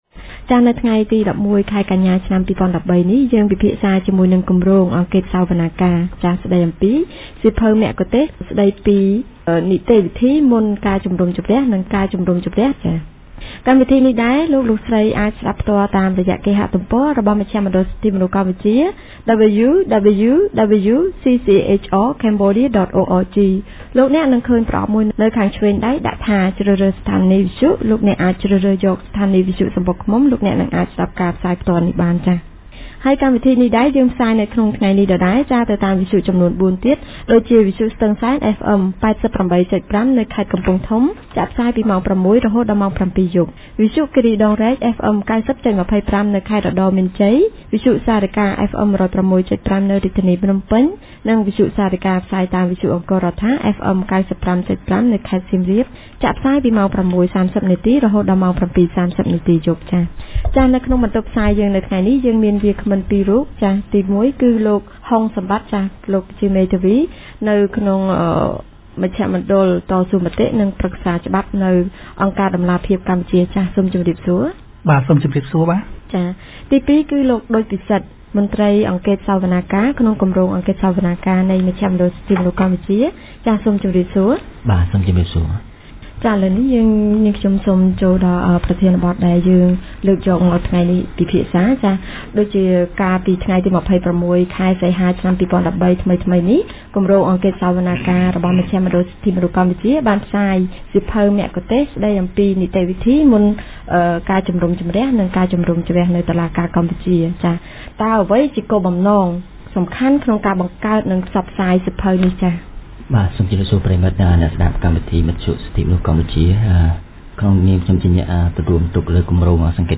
On 11 September 2013, TMP held a radio show presenting the ‘Handbook on pre-trial and trial procedure in the Cambodian courts’ that was released by the project on 26 August 2013, seeking to provide an easy-to-understand overview of the requirements of criminal procedure as stipulated in the Code of Criminal Procedure of the Kingdom of Cambodia to the public and legal professionals. Points raised during the radio show included pre-trial procedures (police interrogation, prosecution and investigation stages) as well as the trial process and appeal rights.